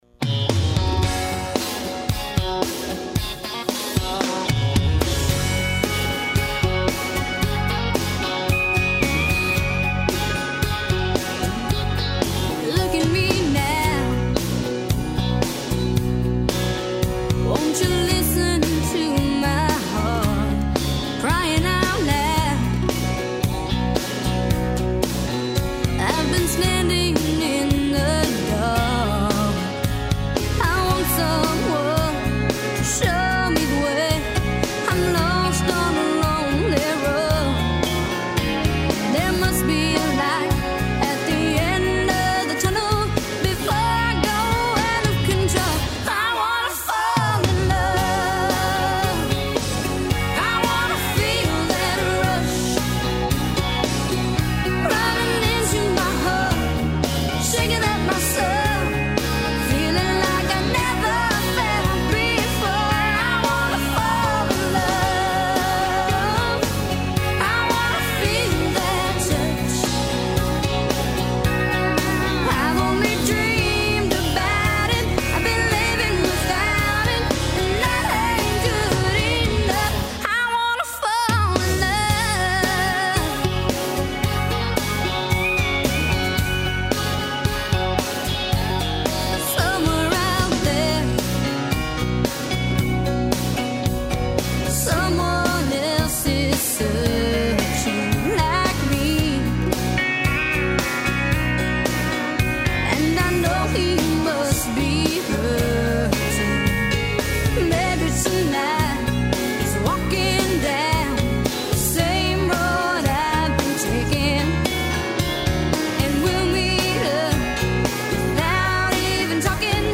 Broadcast live every Tuesday morning at 10am on WTBR.